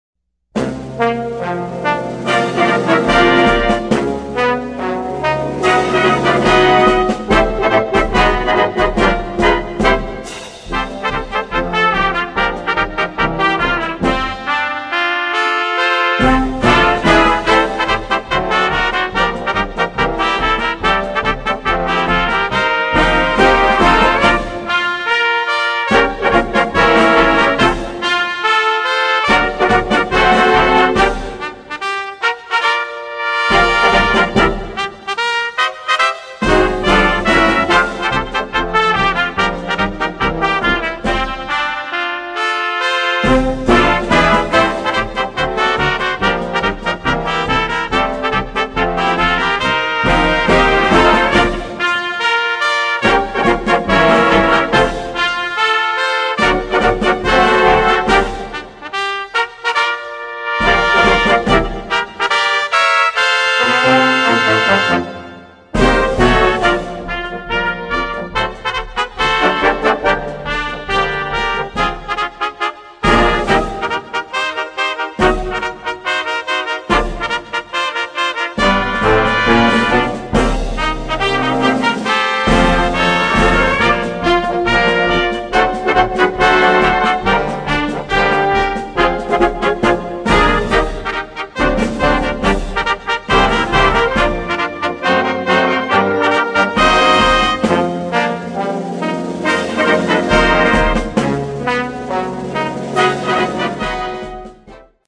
Partitions pour orchestre d'harmonie avec trio de cornet.